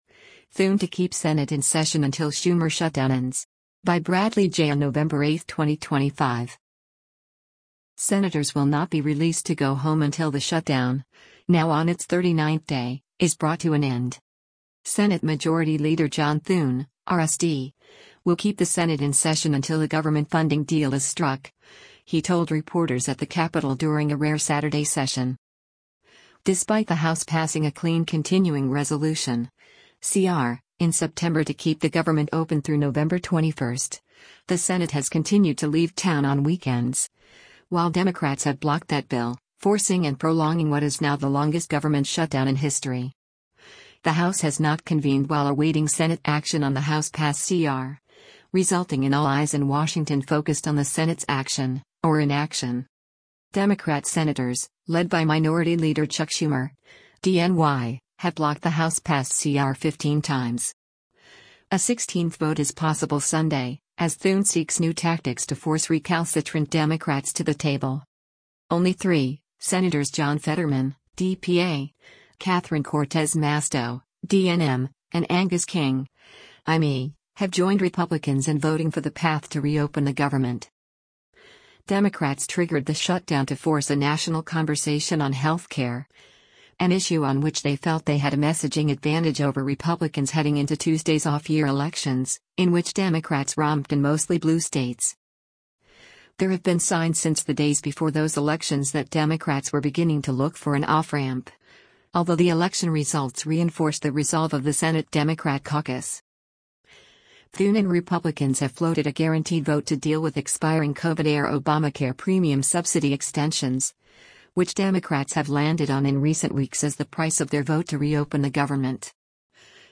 Senate Majority Leader John Thune (R-SD) will keep the Senate in session until a government funding deal is struck, he told reporters at the Capitol during a rare Saturday session.